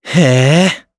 Evan-Vox_Happy4_jp.wav